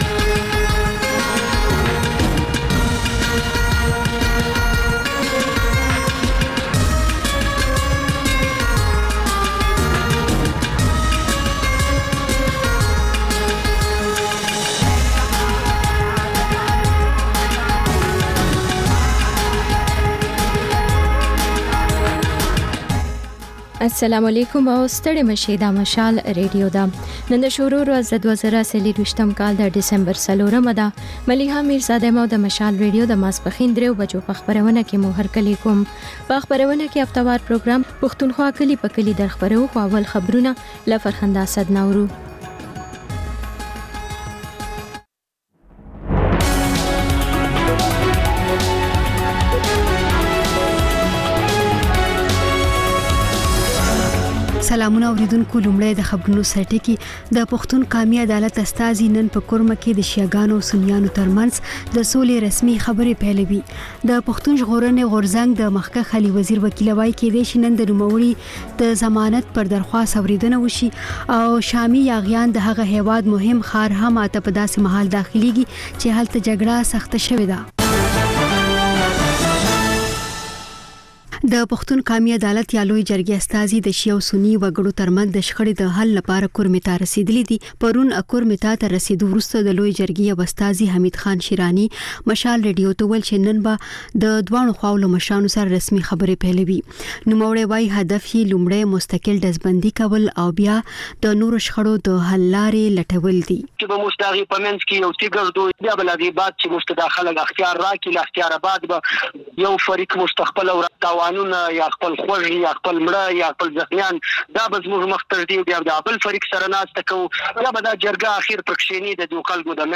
د مشال راډیو درېیمه یو ساعته ماسپښینۍ خپرونه. تر خبرونو وروسته، رپورټونه او شننې خپرېږي.